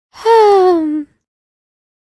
shopkeep-sigh.ogg.mp3